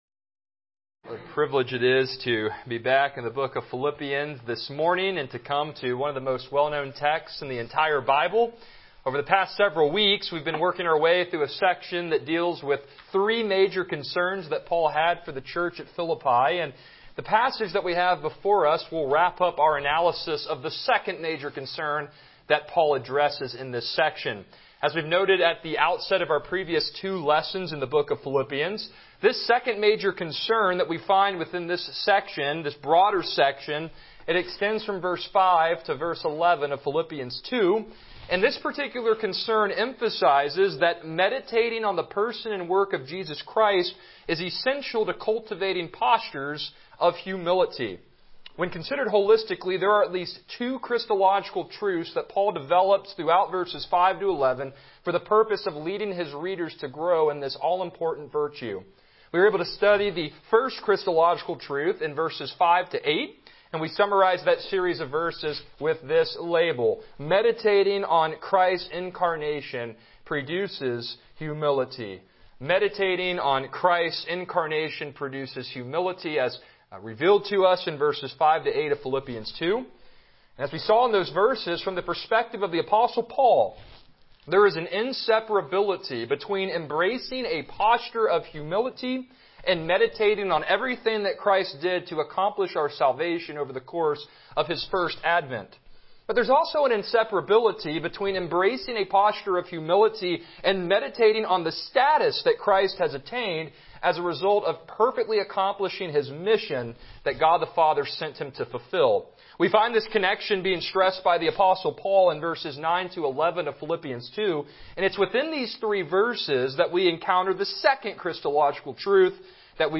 Passage: Philippians 2:9-11 Service Type: Morning Worship